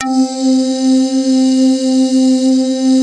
pad1.wav